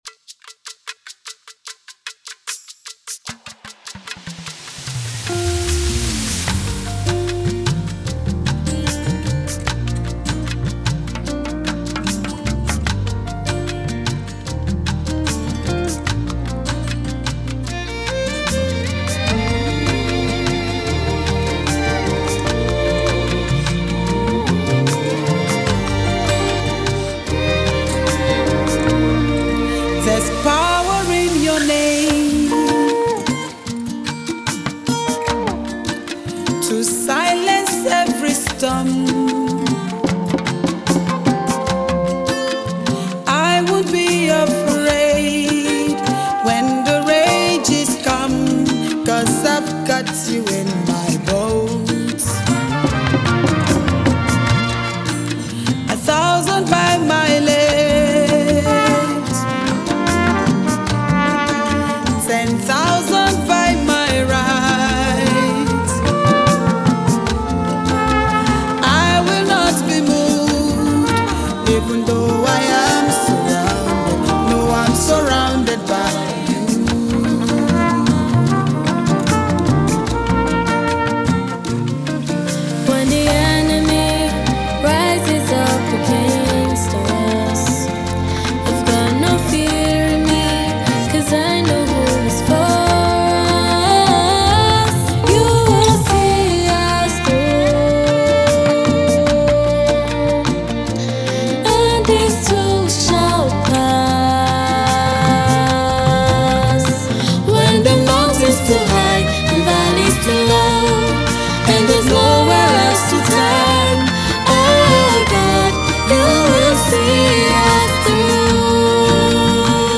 Nigerian gospel music minister and songwriter